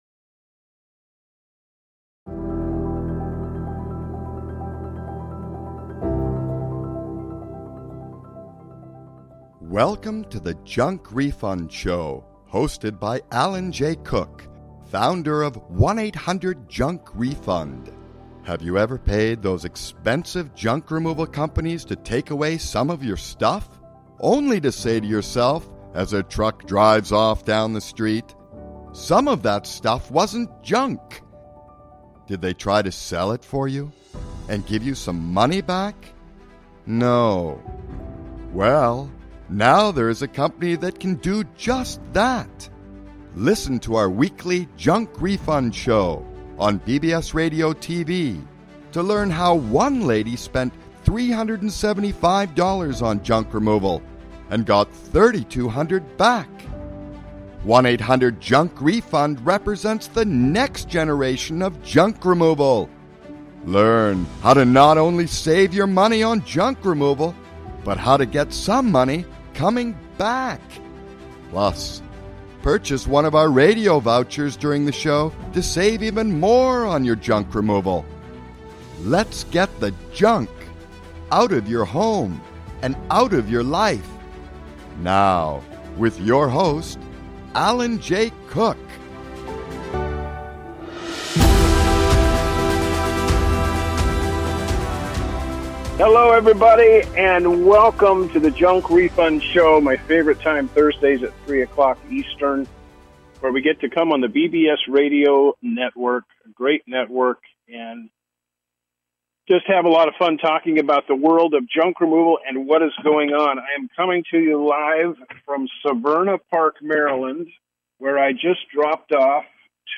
Live from Severna Park, Maryland